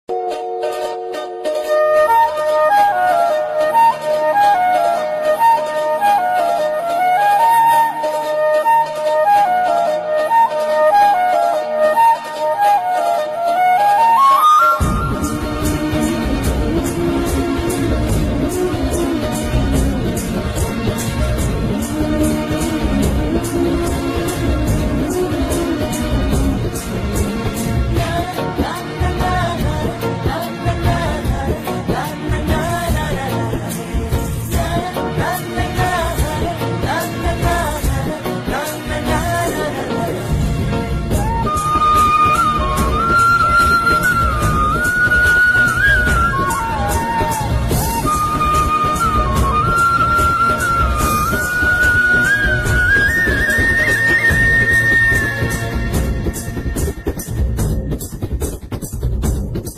Best Tamil Love Ringtone